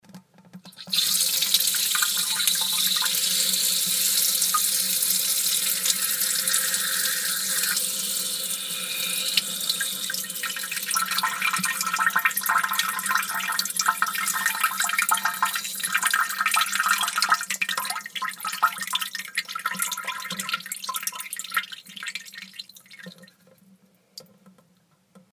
• Качество: высокое
Звук женщины, писающей в унитаз